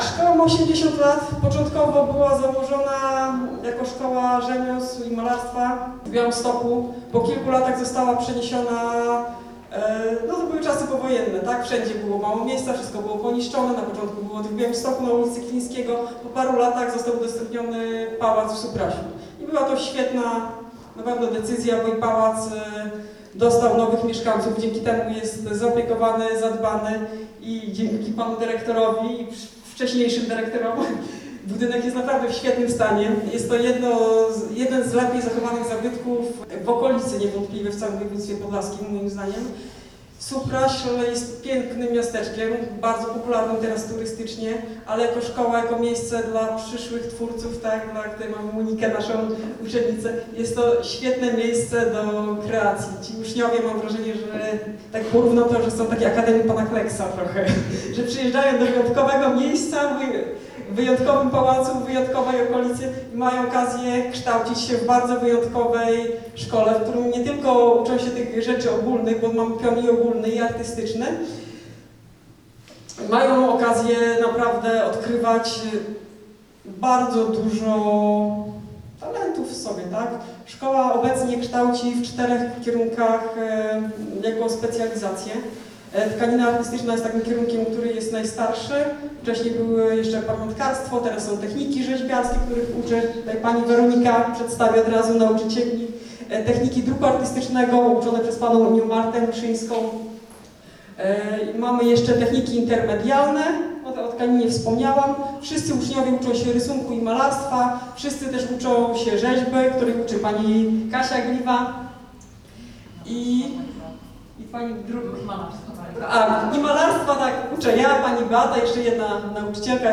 15 III 2024; Suwałki – Galeria Sztuki Współczesnej Chłodna 20 – wernisaż wystawy „Otwarte drzwi”